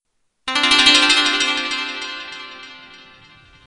Ascending sitar strings